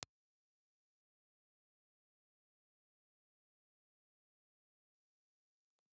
Ribbon
Impulse Response file of National WM-702 in filter position 5
National_WM702_IR_HPF5.wav